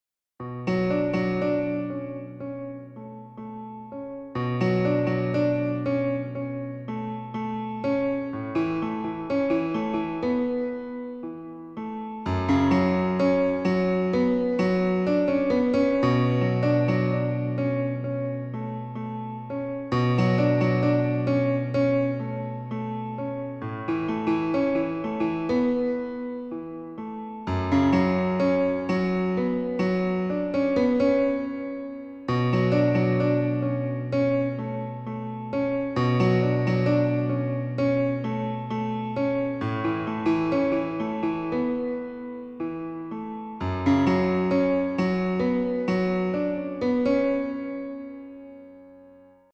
Hello-Piano-1.mp3